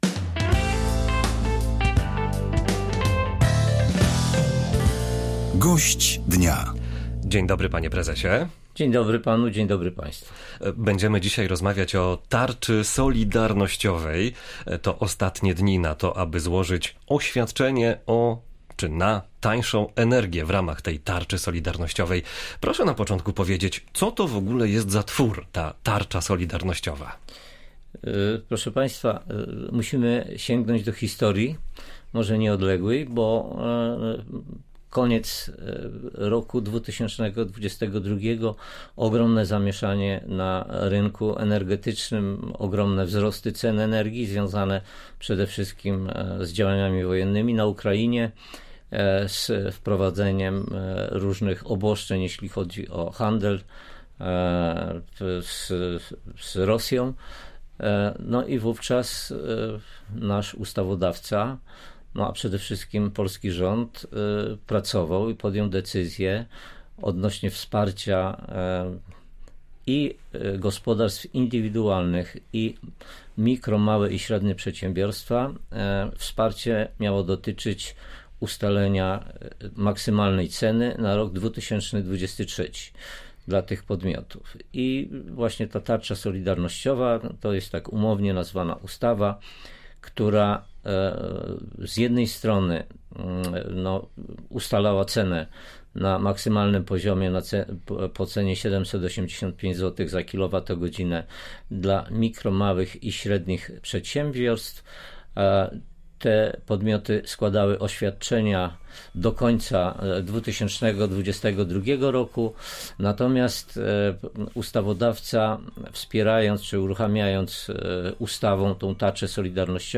-Tarcza to umownie nazwa ustawy, która ustalała maksymalną cenę dla mikro, małych i średnich przedsiębiorstw. Z pomocy mogą skorzystać też gospodarstwa domowe – przypomniał gość Polskiego Radia Rzeszów.